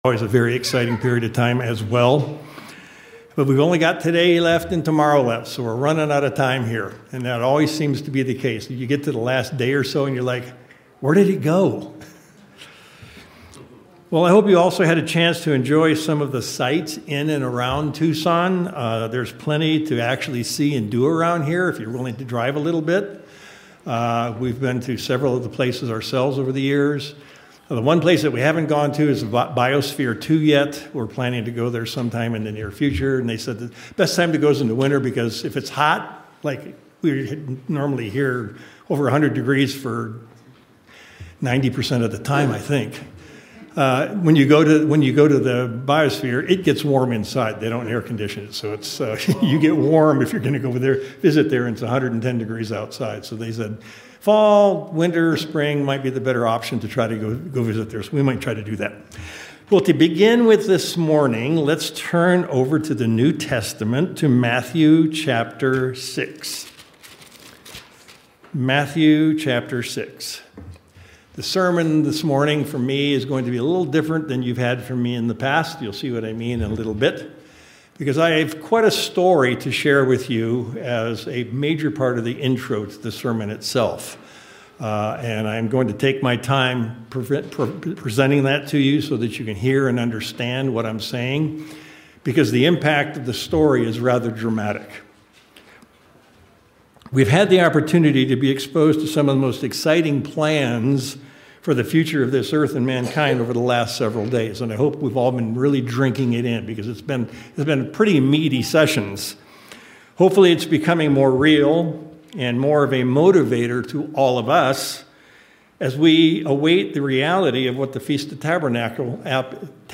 Sermons
Given in Tucson, AZ El Paso, TX